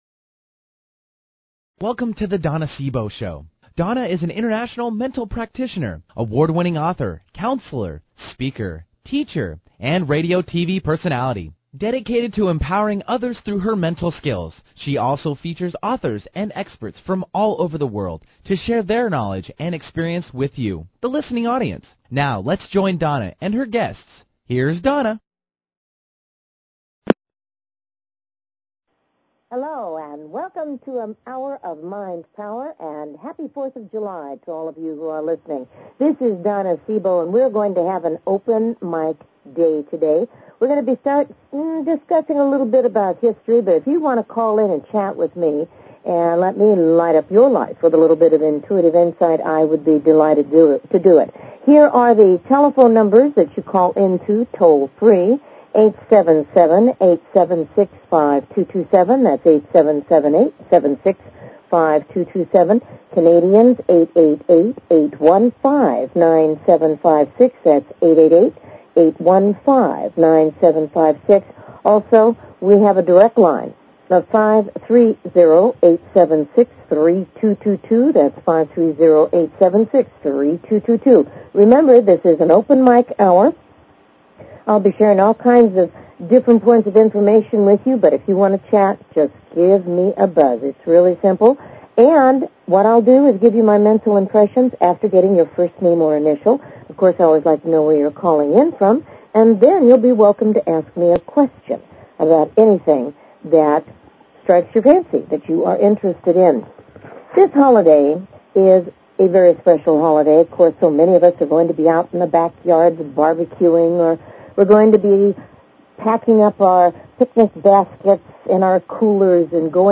Talk Show Episode
Happy Fourth of July. This will be an open mike program